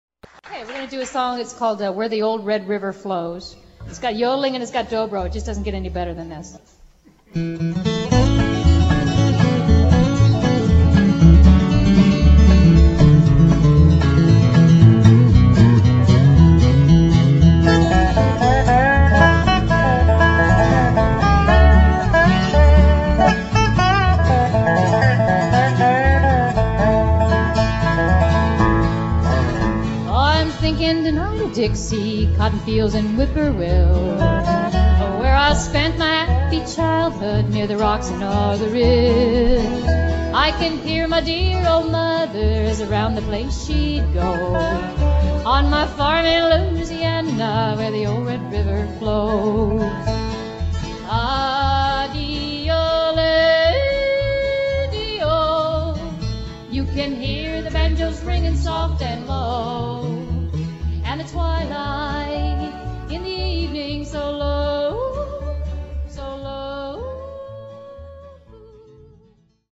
Lead Vocals / Rhythm Guitar
Lead Guitar, Mandolin / Vocals
String Bass
Banjo